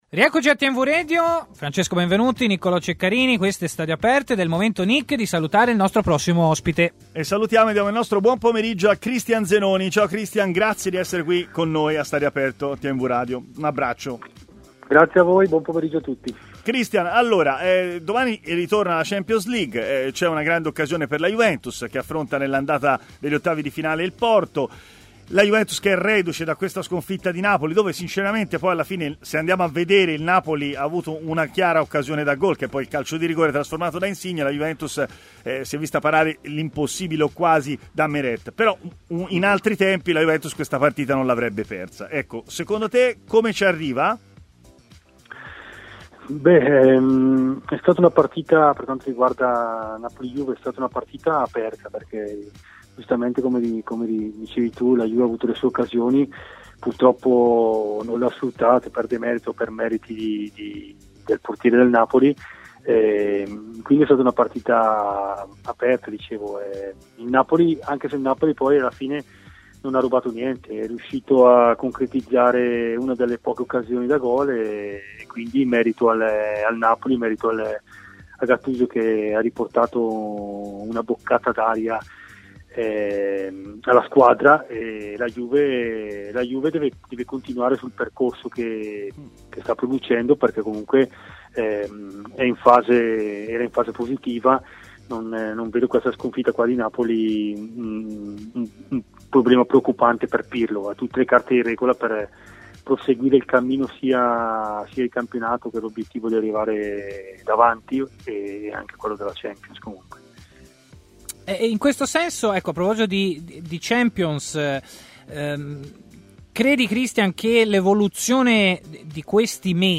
L'ex difensore Cristian Zenoni ha parlato a TMW Radio, nel corso della trasmissione Stadio Aperto